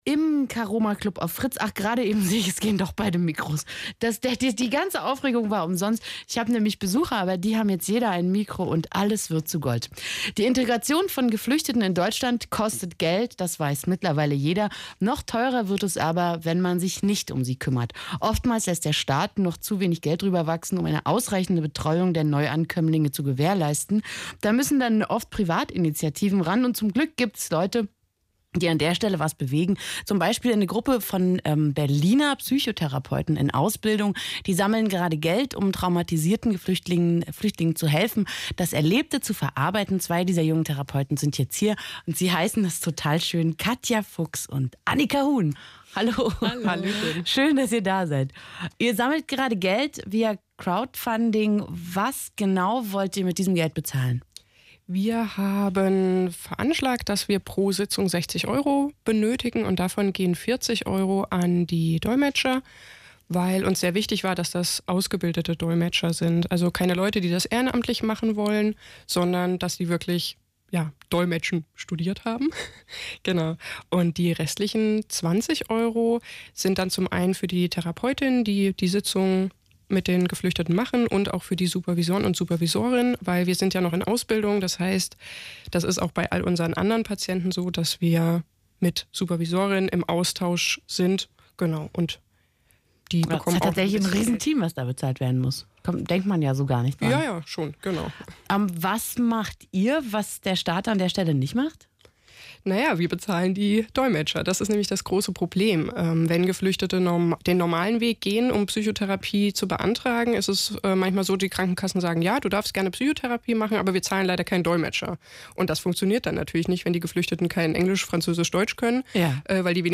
Interview bei Radio Fritz
Fritz-Mitschnitt-Prothege.mp3